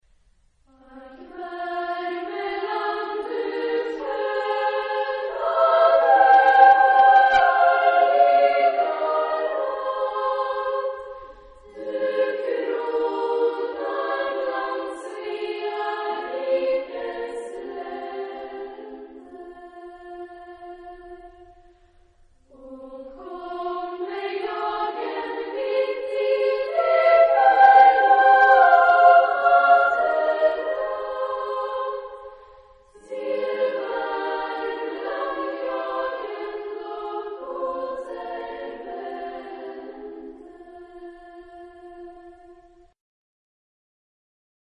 ... Swedish folksong ...
Genre-Style-Forme : Chanson ; Populaire ; Folklore ; Profane
Type de choeur : SSAA  (4 voix égales de femmes )
Tonalité : fa mineur